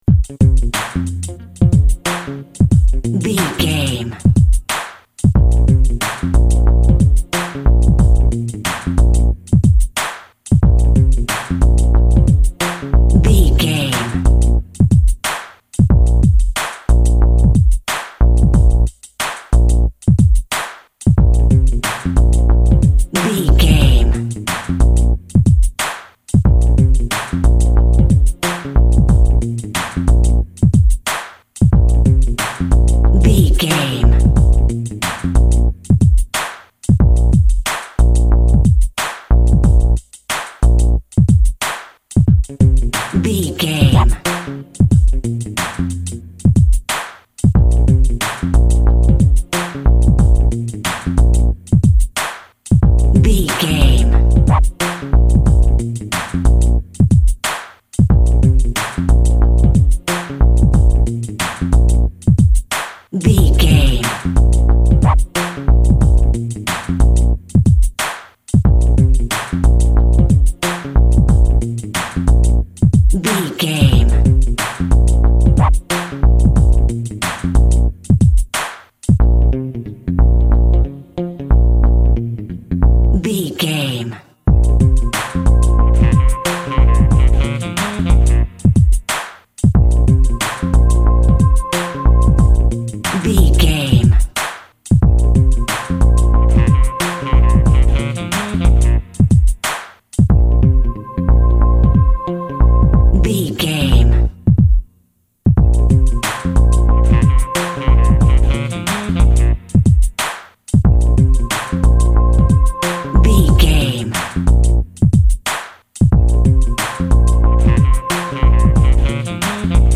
Aeolian/Minor
B♭
turntables
synth lead
synth bass
hip hop synths
electronics